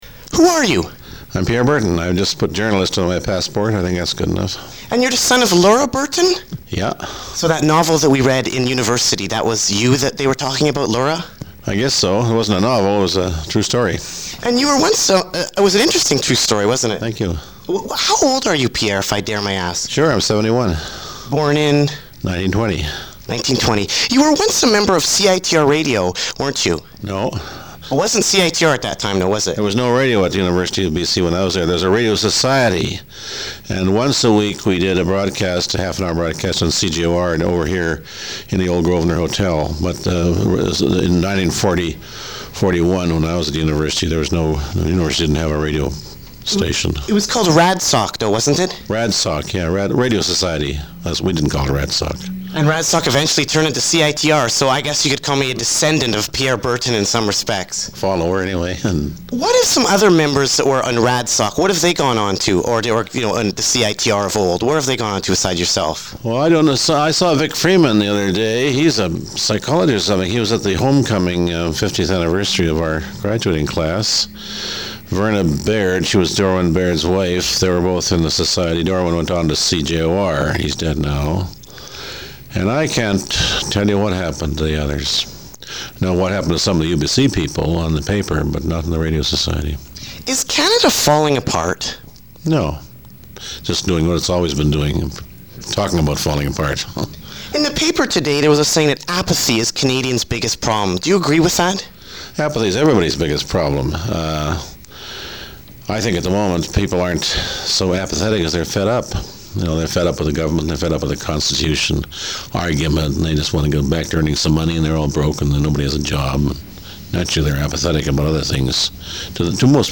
Radio Interviews Archives - Page 64 of 64 - Nardwuar the Human Serviette Radio Show!